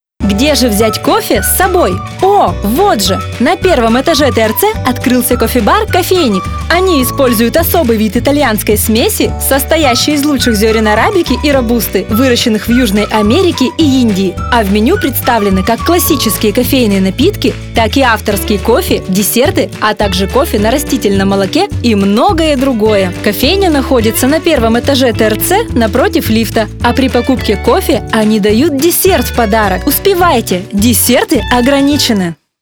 Дикторы женщины.
Женский голос онлайн для рекламы и сообщений!